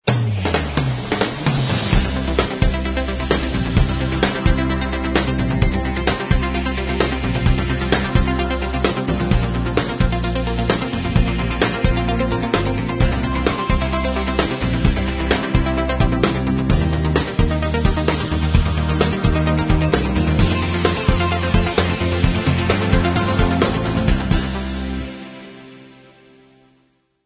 All tracks encoded in mp3 audio lo-fi quality.